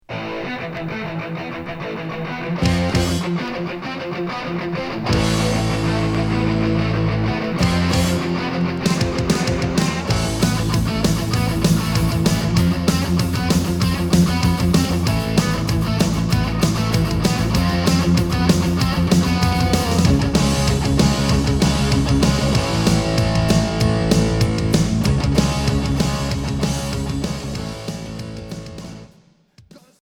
Hard heavy Unique 45t retour à l'accueil